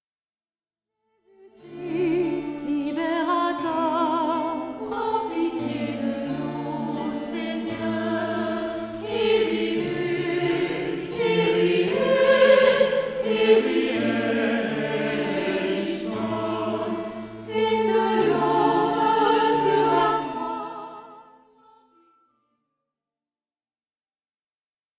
Format :MP3 256Kbps Stéréo